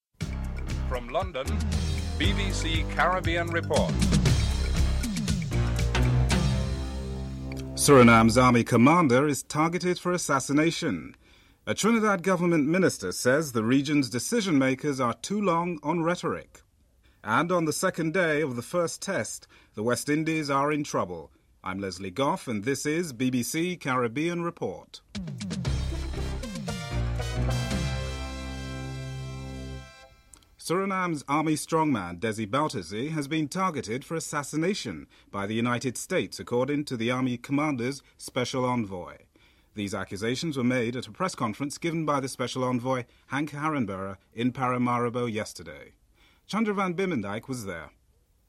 1. Headlines (00:00-00:30)
5. William Morris, a Jamaican-born trade unionist in Britain, is elected as the leader of Britain’s largest union, Transport and General Workers Union.